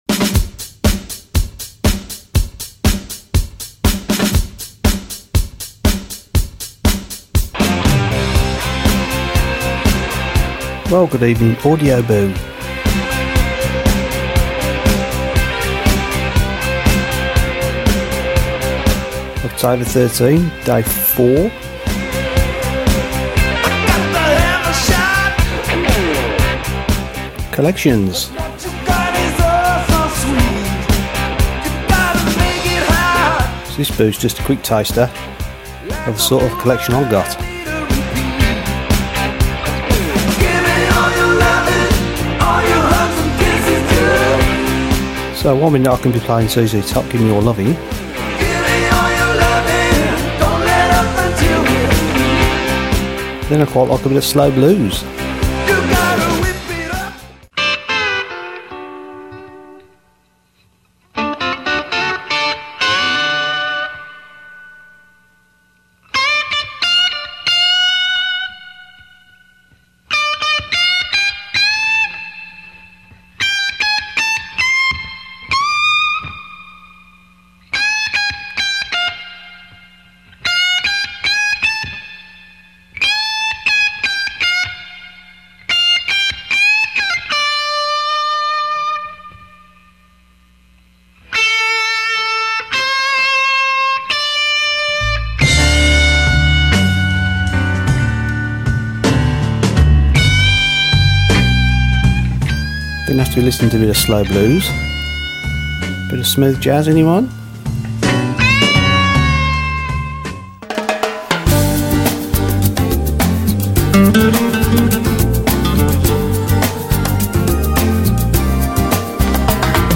Slow blues